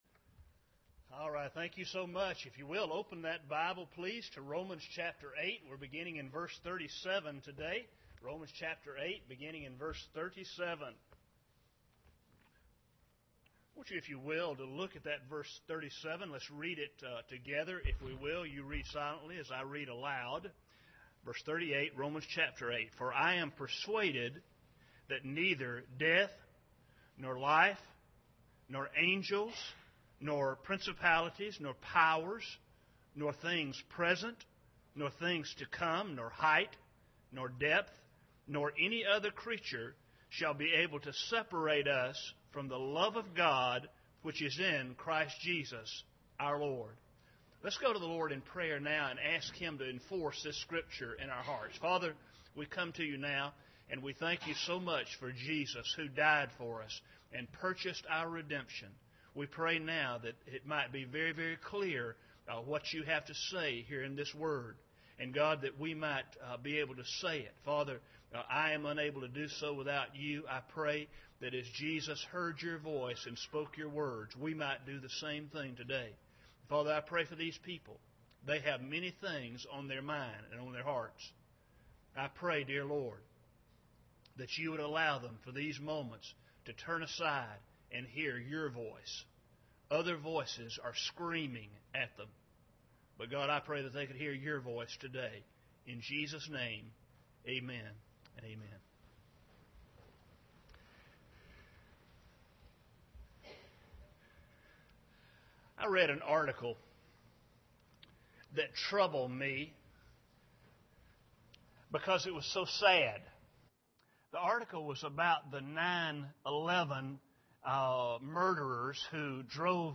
Romans 8:38-39 Service Type: Sunday Morning Bible Text